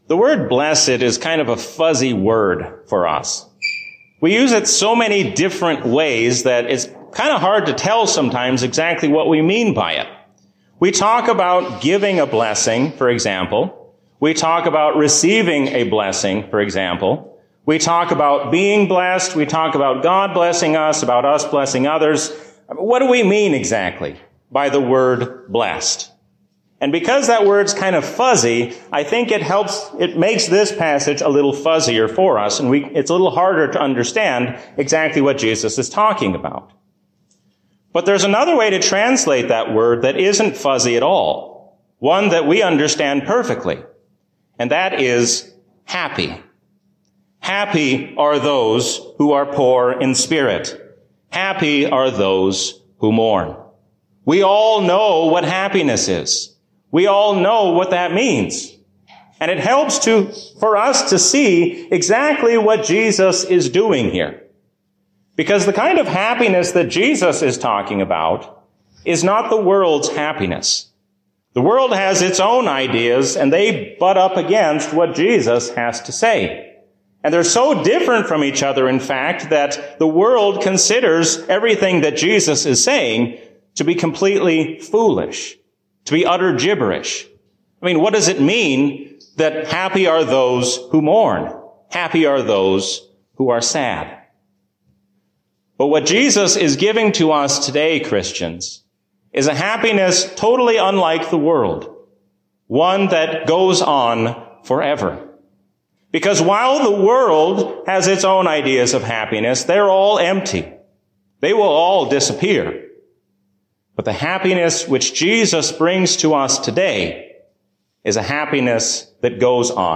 A sermon from the season "Pentecost 2023."